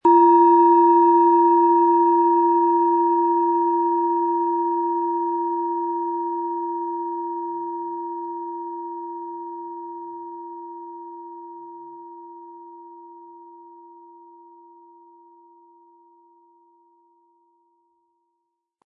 Planetenton 1
Chiron
Nach uralter Tradition von Hand getriebene Klangschale.
Um den Original-Klang genau dieser Schale zu hören, lassen Sie bitte den hinterlegten Sound abspielen.
SchalenformBihar
MaterialBronze